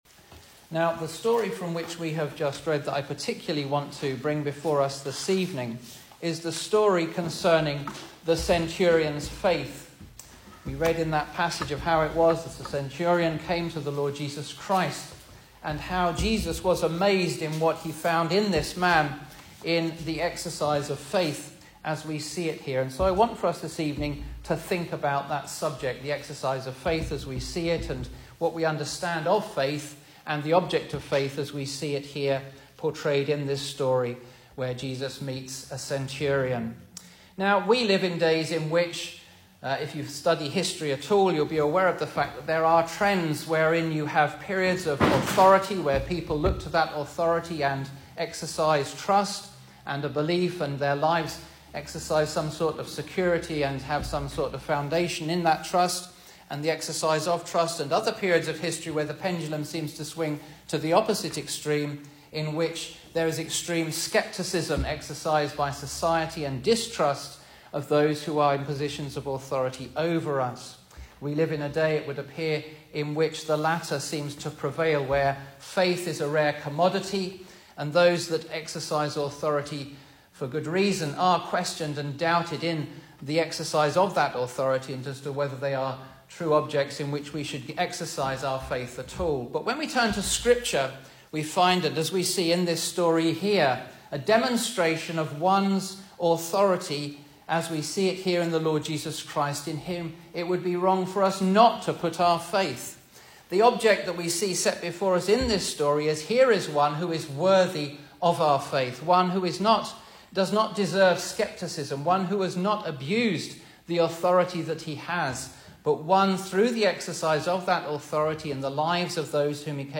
Service Type: Sunday Evening
Single Sermons